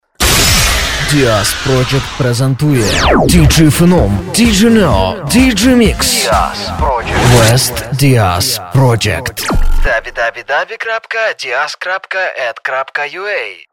Аудіо-джингл